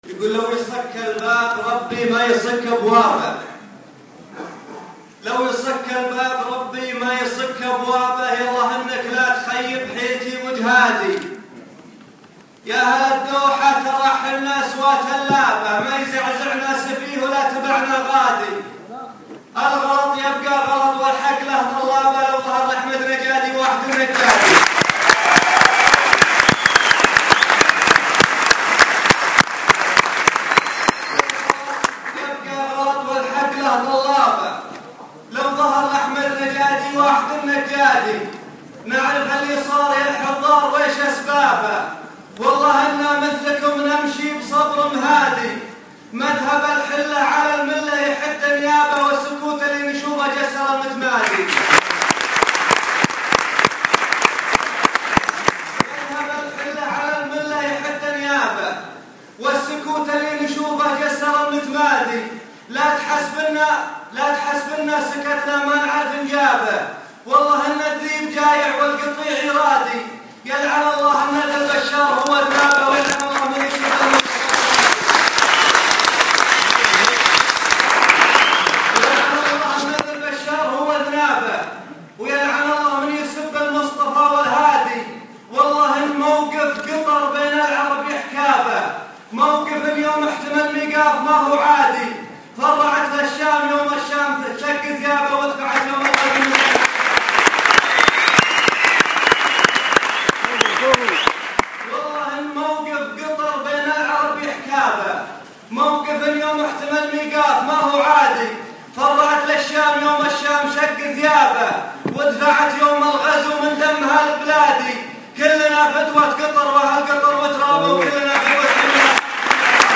ياأهل الدوحه [ امسيه كتارا ]